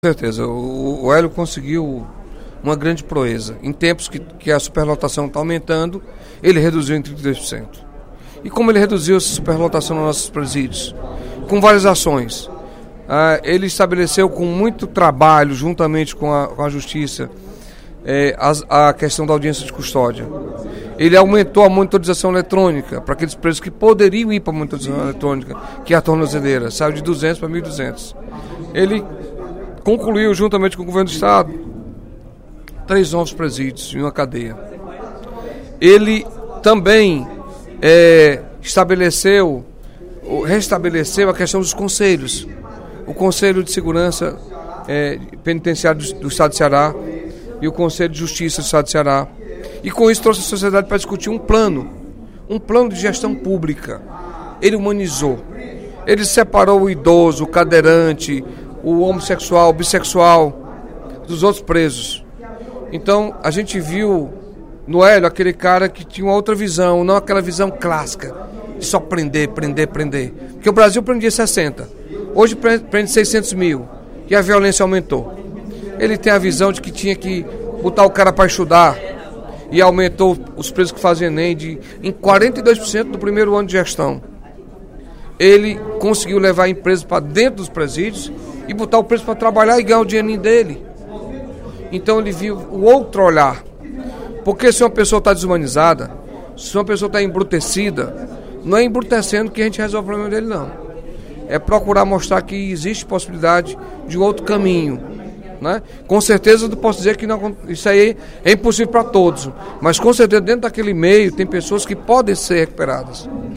O deputado Carlos Felipe (PCdoB) destacou, durante o primeiro expediente da sessão plenária da Assembleia Legislativa desta quinta-feira (09/02), a atuação do advogado Hélio Leitão à frente da Secretaria de Justiça do Estado.